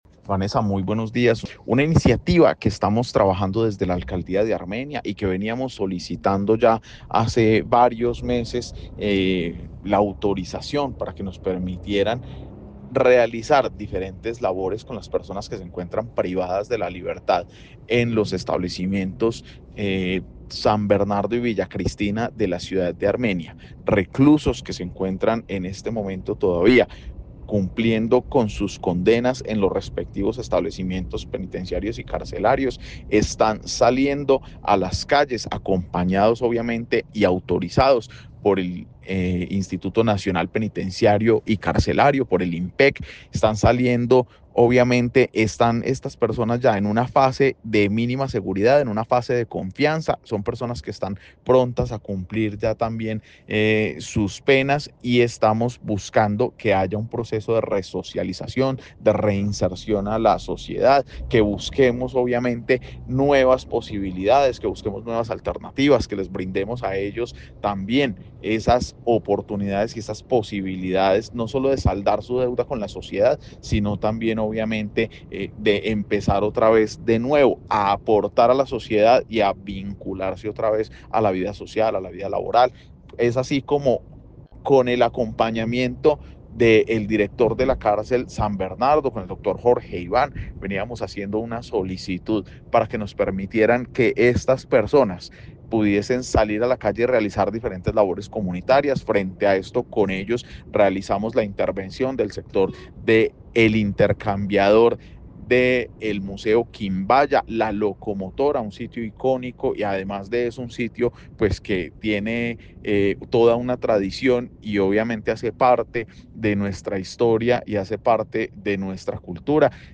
Secretario de Gobierno sobre restauración